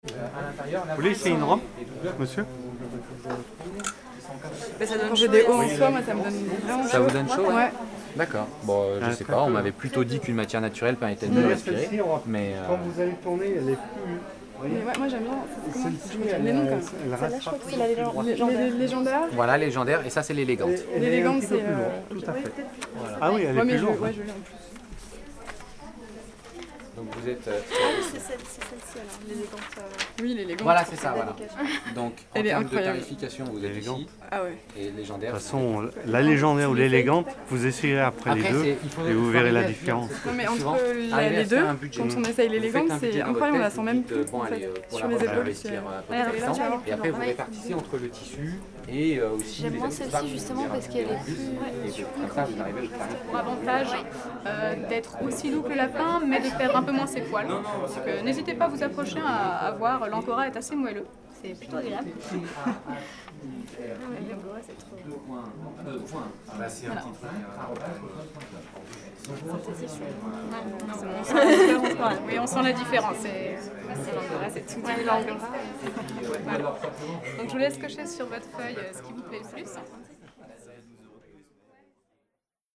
Strasbourg, école d'avocats - KM150 / 4minx